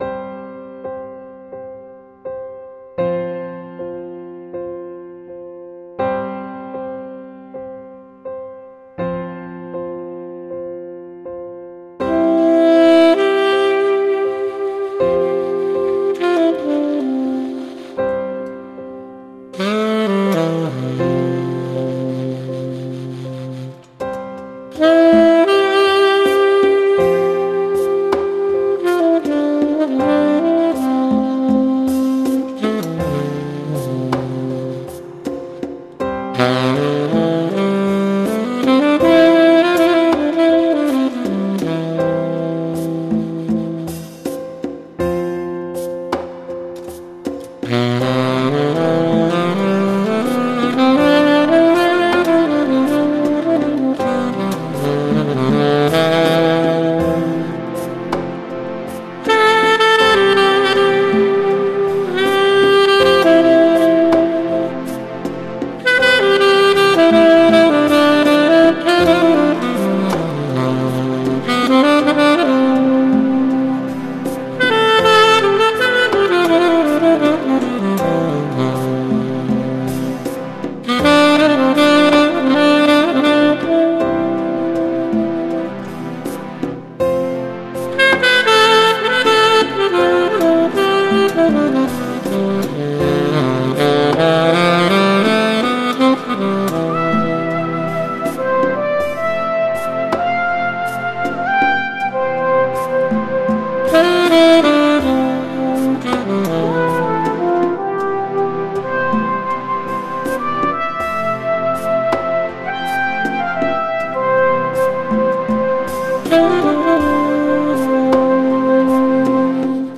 ein Instrumentalstück aus dem Jahr 2007